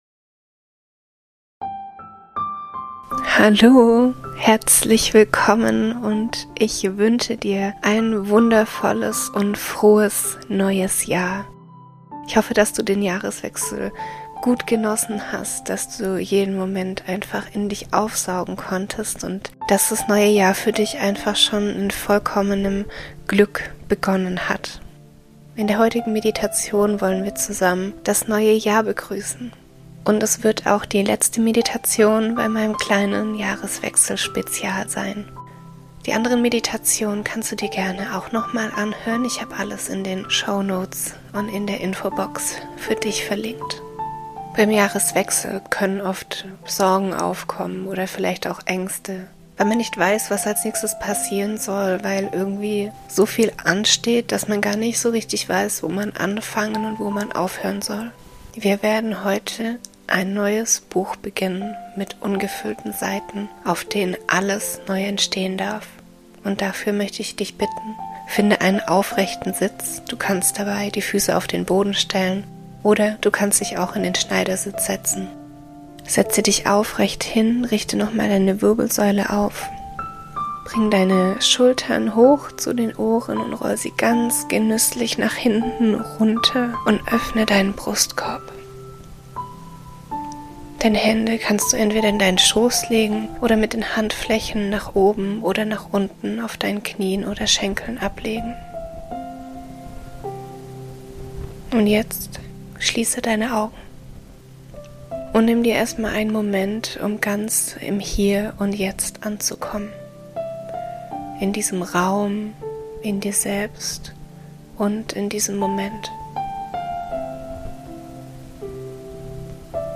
In der heutigen Meditation kannst du dich mit deiner Schöpferkraft verbinden.